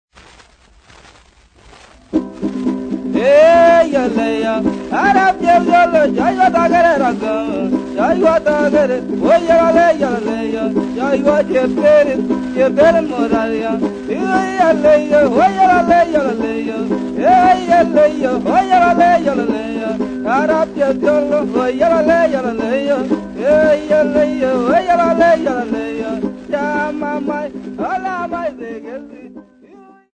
Folk Music
Field recordings
Africa Kenya city not specified f-ke
sound recording-musical
Indigenous music